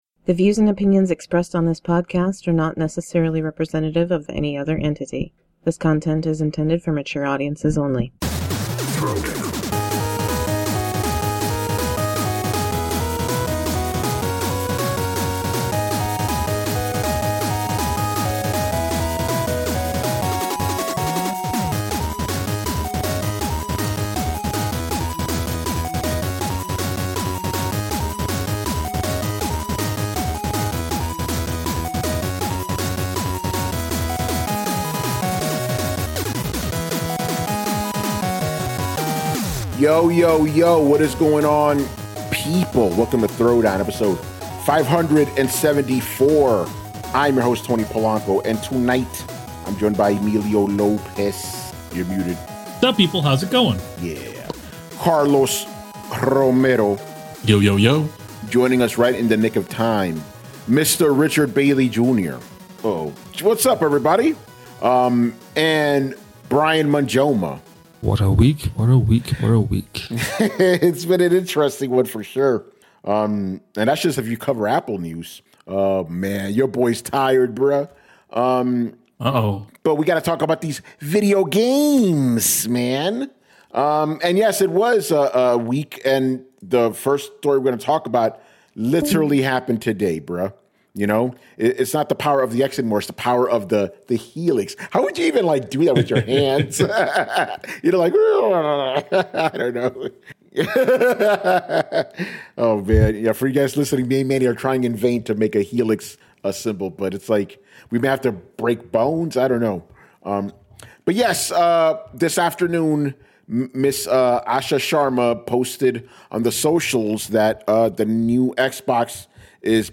intro and outro music